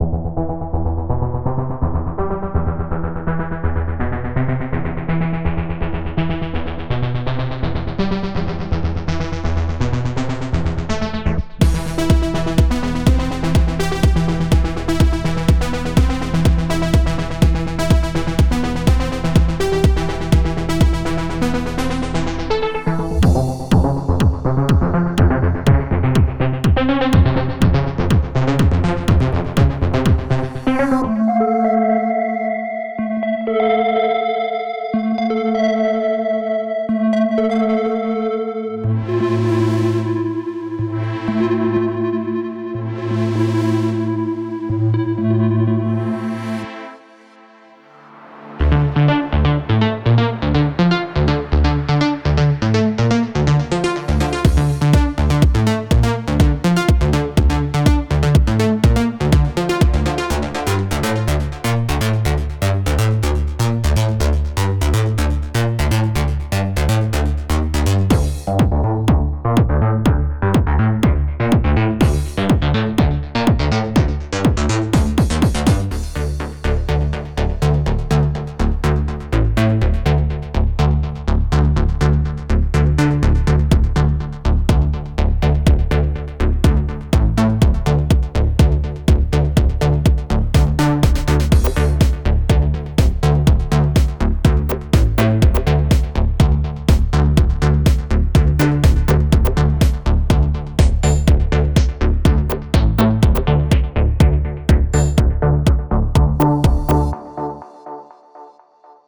Type: Samples
House Minimal Multi-genre Tech House
23 Bass Loops
33 Synth Loops
96 Chord Shots
31 Percussion Shots